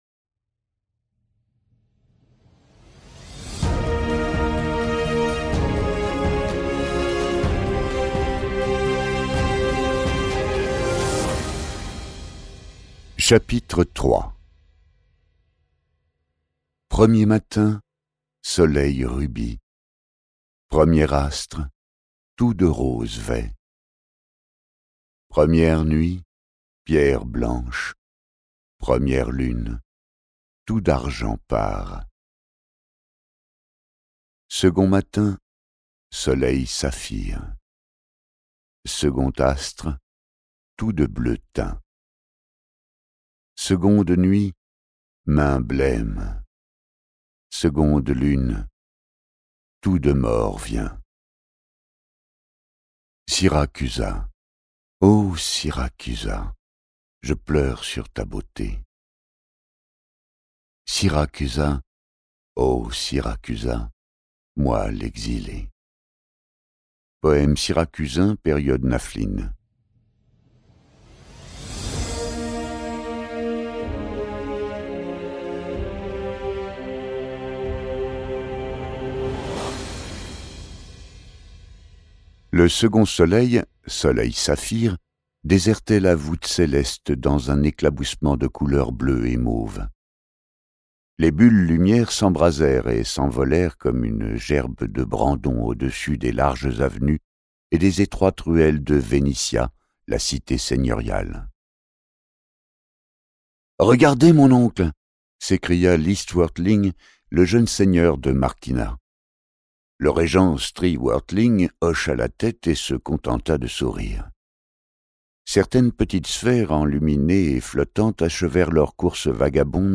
Car sa vie bascule le jour où une belle Syracusaine, traquée, passe la porte de son agence...Ce livre audio est interprété par une voix humaine, dans le respect des engagements d'Hardigan.